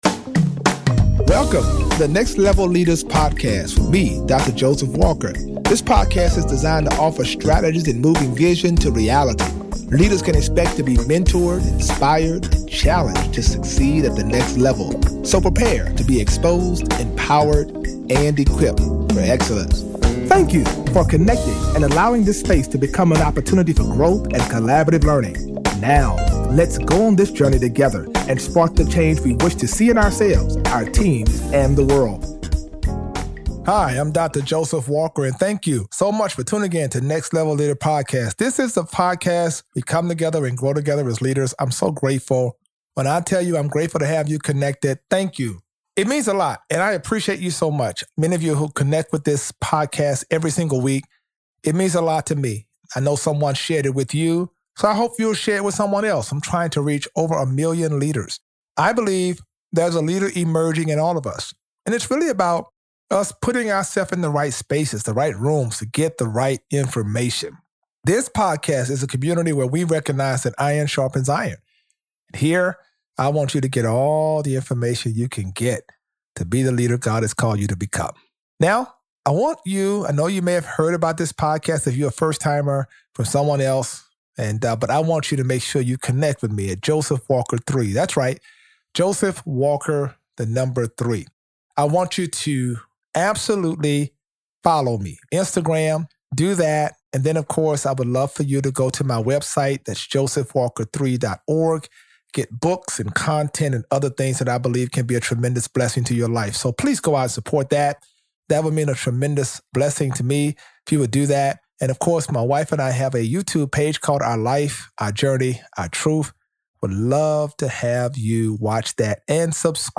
Each episode addresses the intersect between Christianity and the marketplace through conversations with successful leaders. Listeners will be mentored, inspired, and challenged to succeed at the next level.